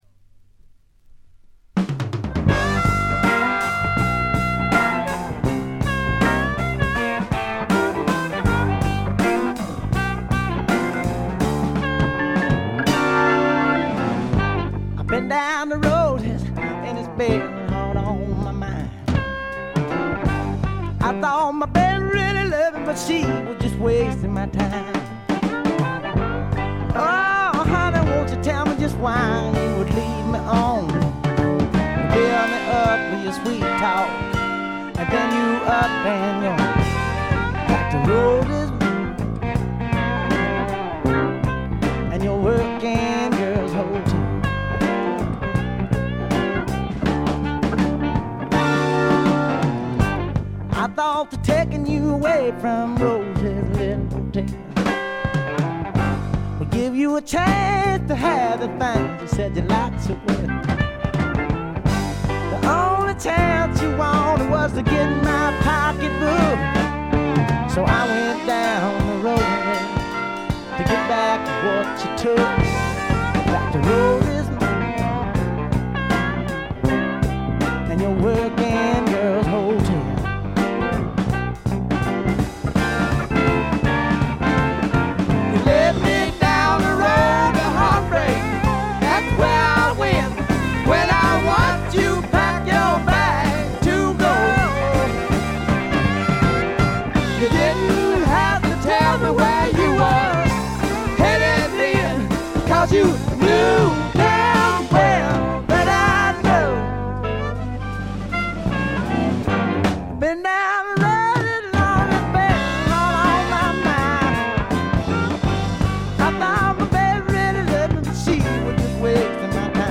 これ以外は軽微なバックグラウンドノイズ程度。
びしっと決まった硬派なスワンプ・ロックを聴かせます。
試聴曲は現品からの取り込み音源です。
Recorded at Paramount Recording Studio.